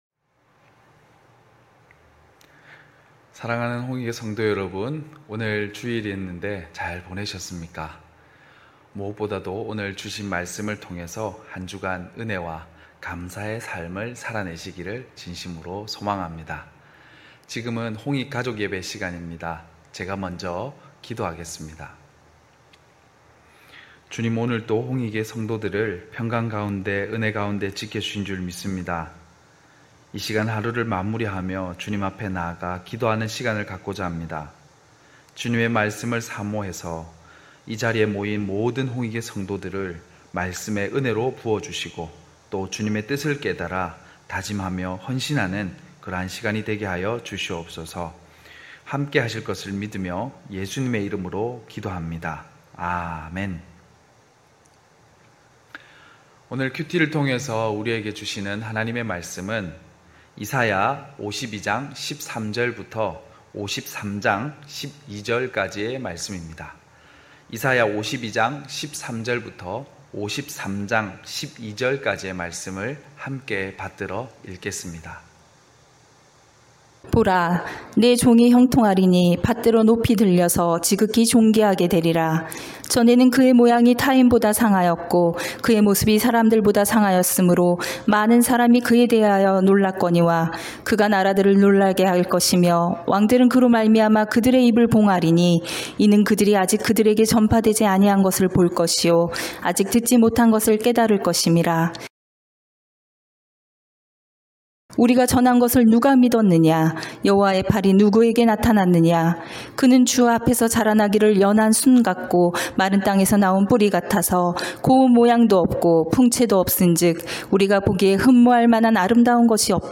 9시홍익가족예배(8월1일).mp3